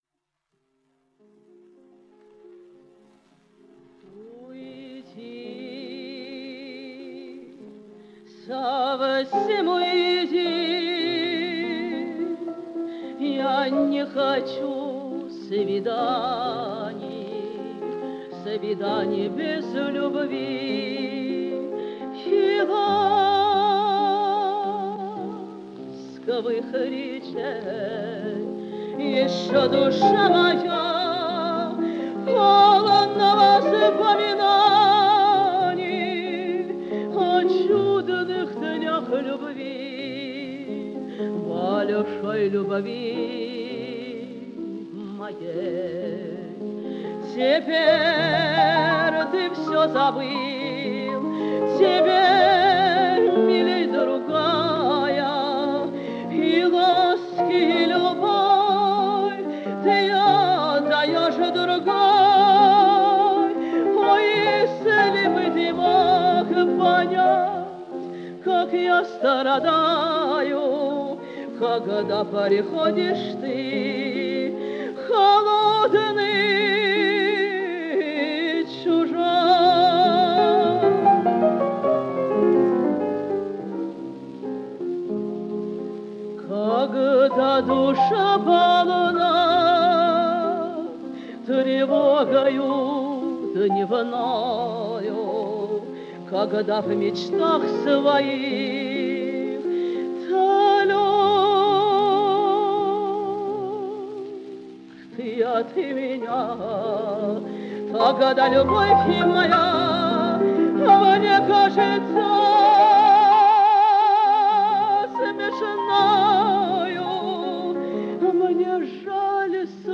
Жанр: Романсы